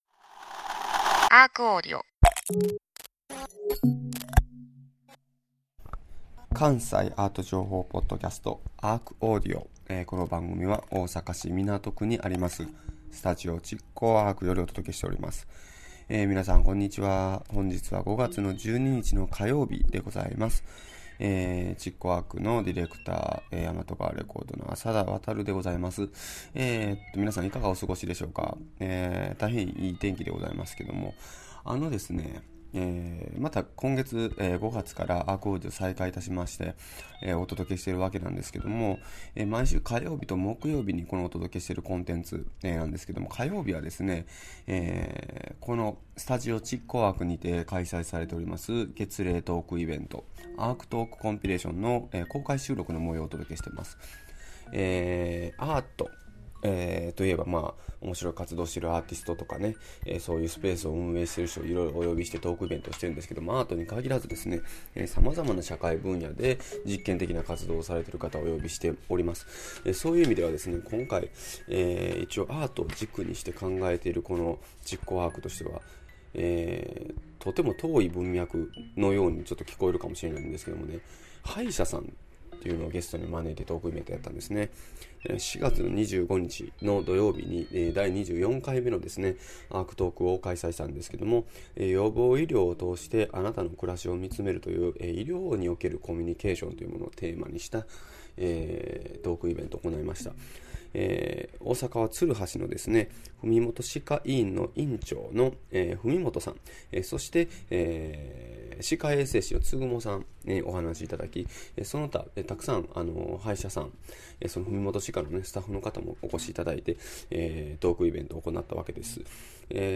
5/12(火) ARCAudio!! トーク「予防医療を通して、あなたの暮らしをみつめる」1/3